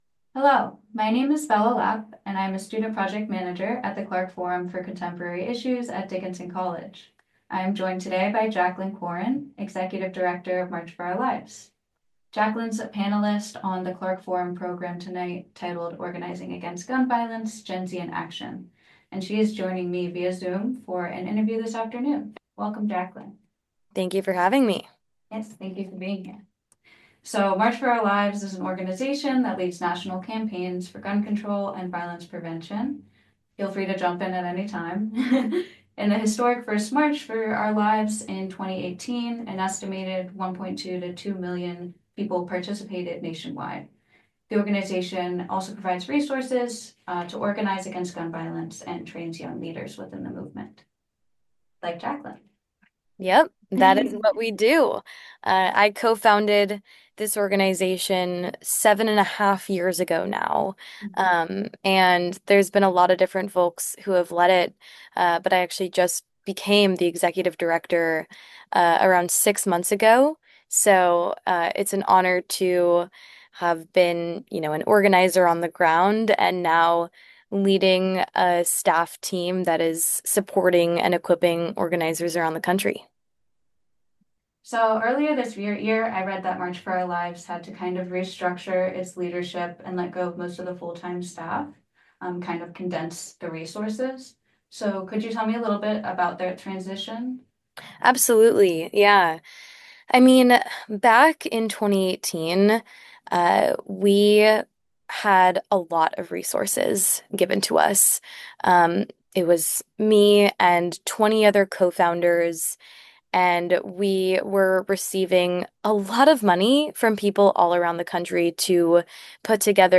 Interview with Jaclyn Corin, Survivor of the 2018 Parkland shooting; Executive Director of March For Our Lives